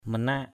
/mə-na:ʔ/ (cv.) panak pq%K [Cam M] (t.) yểu, chết non = mourir subitement = premature death. manak ayuh mq%K ay~H yểu tướng = voué à mourir jeune = doomed to die young....
manak.mp3